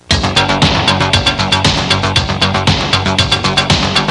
Funky Intro Sound Effect
Download a high-quality funky intro sound effect.
funky-intro.mp3